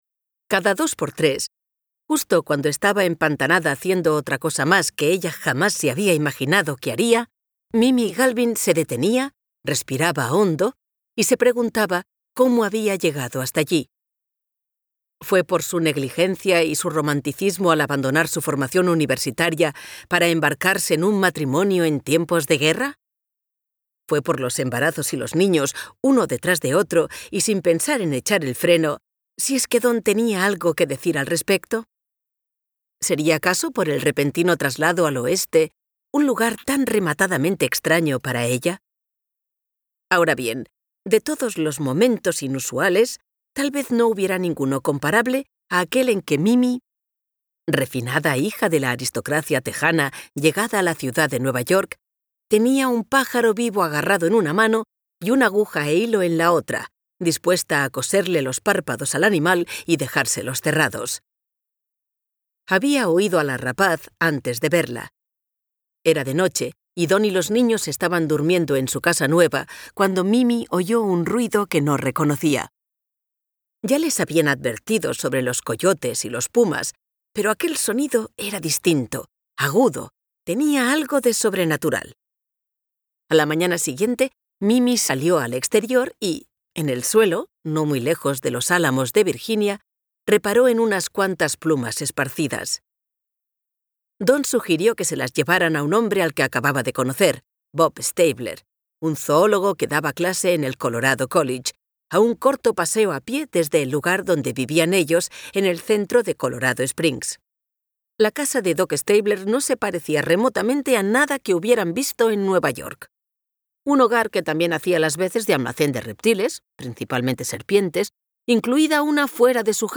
Audiolibro Los chicos de Hidden Valley Road (Hidden Valley Road)